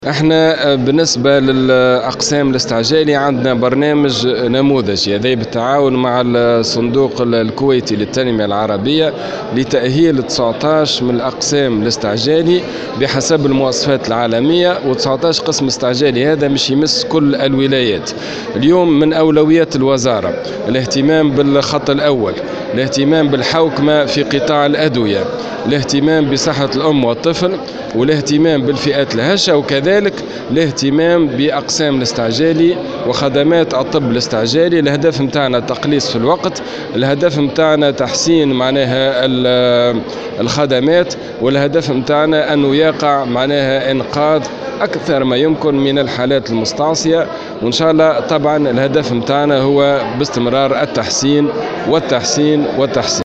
وأضاف في تصريح لبعوثة الجوهرة اف ام إلى مجلس نواب الشعب، على هامش حضوره جلسة عامة، أن أولويات الوزارة تتمثّل في الحوكمة في قطاع الأدوية وصحّة الأم والطفل والفئات الهشة، والتقليص في مدة الانتظار في أقسام الاستعجالي وتحسين الخدمات.